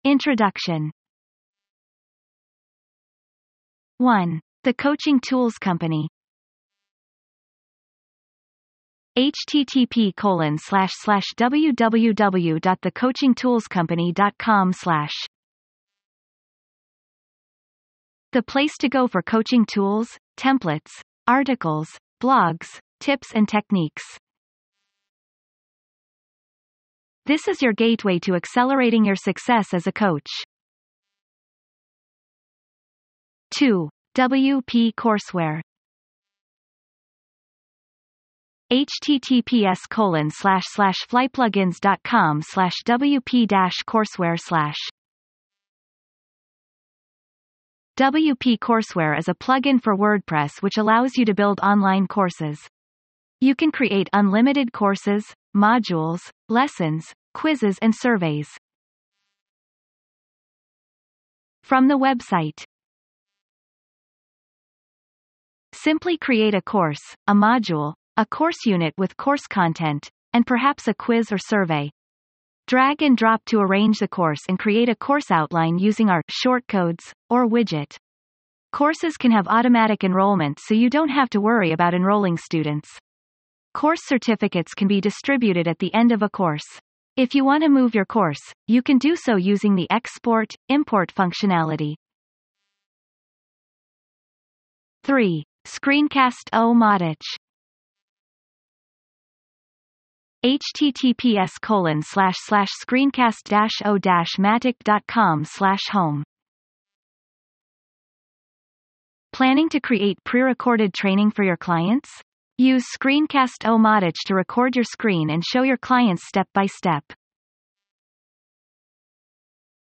This AI-narrated audio guide reveals 10 coaching tools to streamline your workflow, show up consistently, and grow your business with confidence.